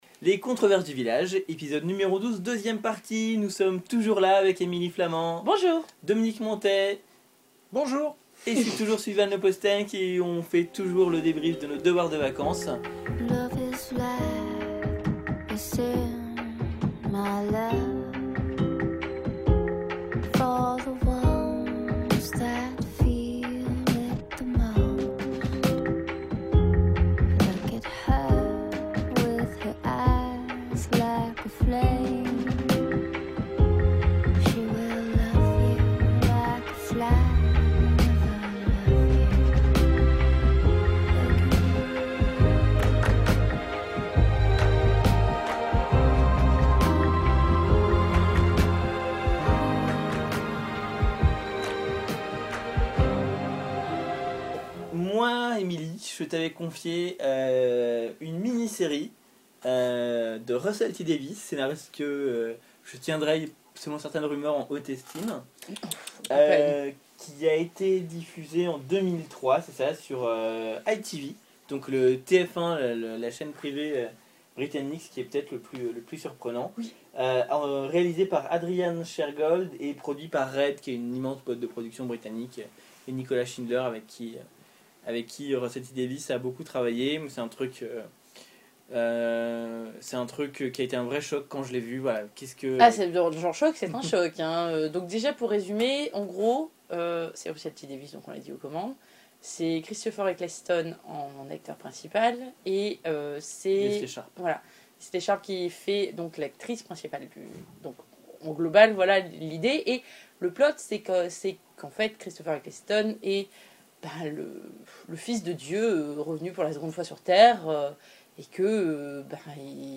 L’heure est maintenant venue pour les membres de la rédaction de livrer leurs impressions et d’en débattre.